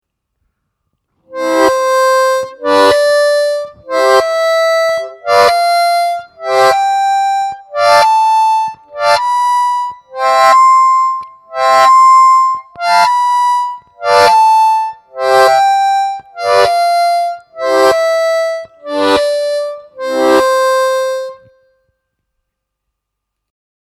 Играть гамму «до мажор» +4-4+5-5+6-6-7+7 при помощи техники блокировки языком, при этом языком закрывать необходимые отверстия как-будто с опозданием.
Gamma-s-blokirovkoy.mp3